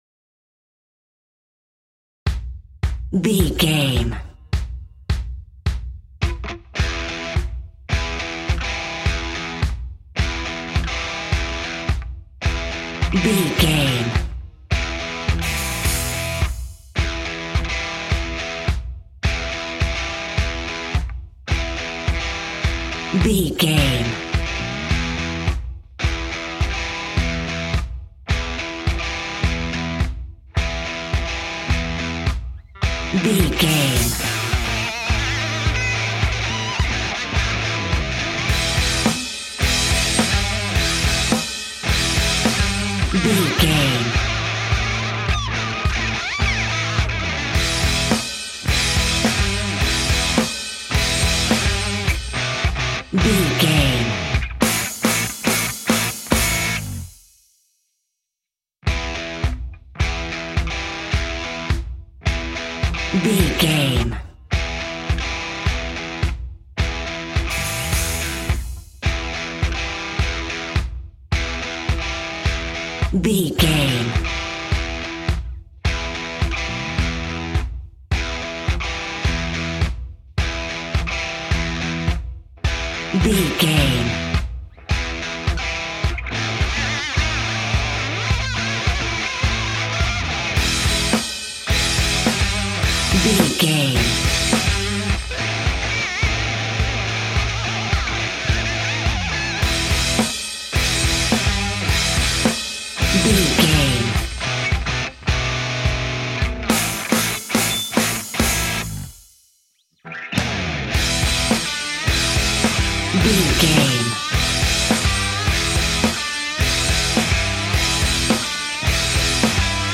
Aeolian/Minor
hard rock
blues rock
distortion
Rock Bass
Rock Drums
heavy drums
distorted guitars
hammond organ